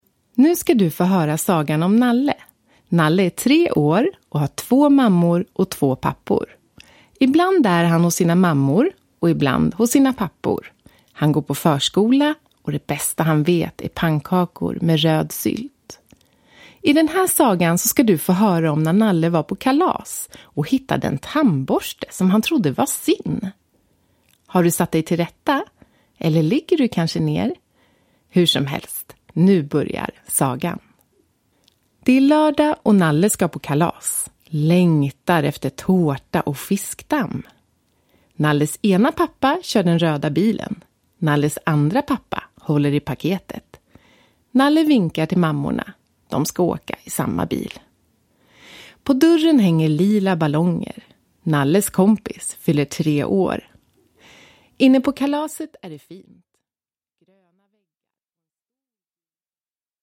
Nalle vill – Ljudbok